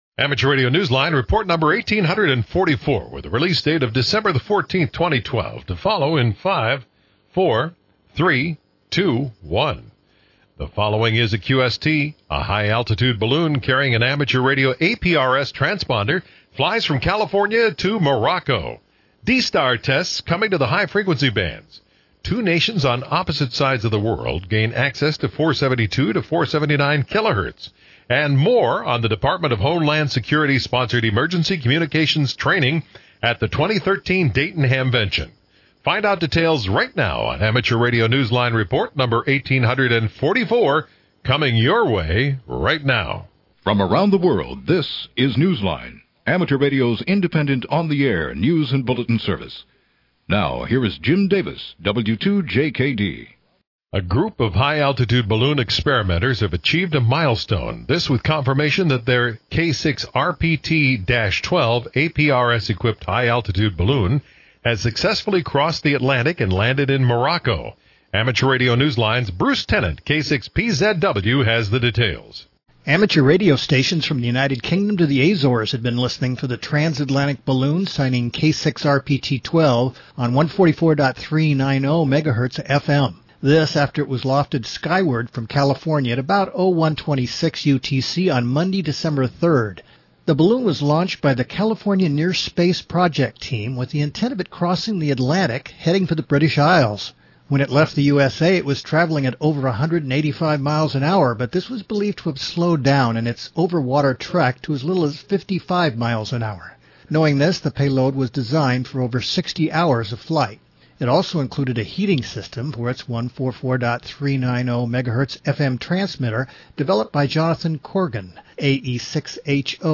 THIS WEEKS NEWSCAST Script Audio